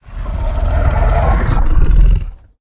chimera_idle_2.ogg